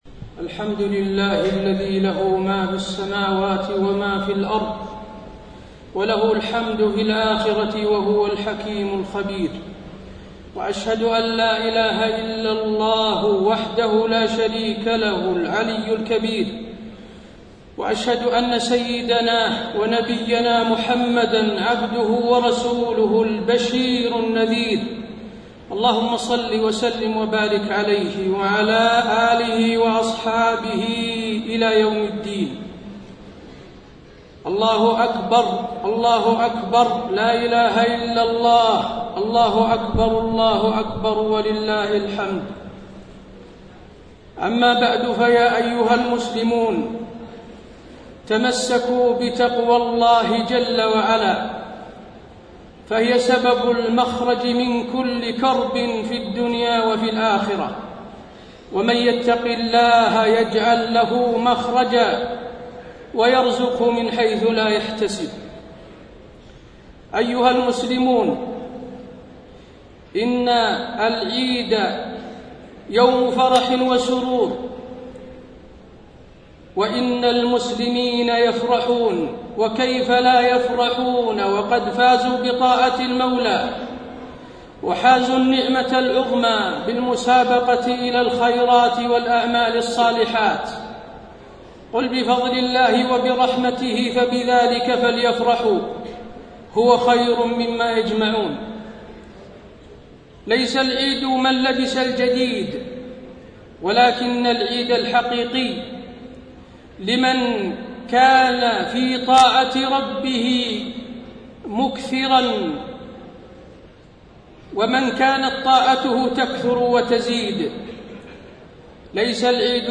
خطبة عيد الأضحى - المدينة - الشيخ حسين آل الشيخ - الموقع الرسمي لرئاسة الشؤون الدينية بالمسجد النبوي والمسجد الحرام
المكان: المسجد النبوي